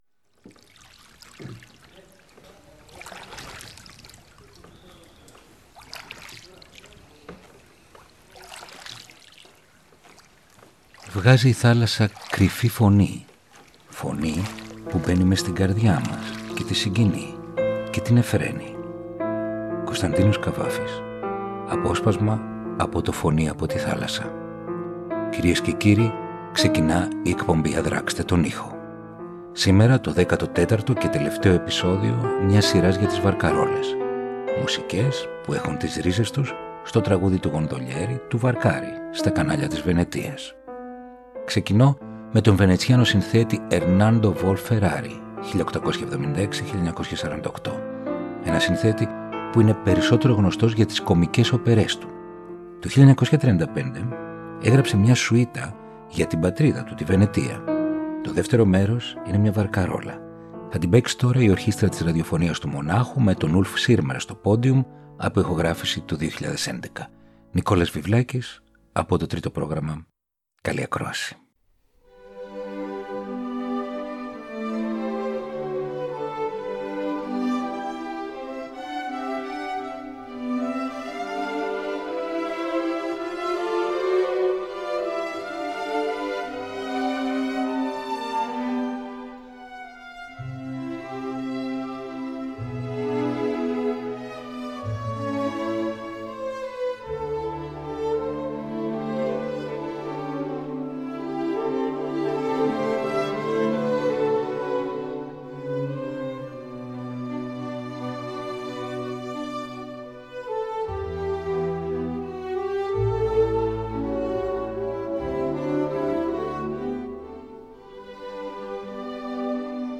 Βαρκαρόλες.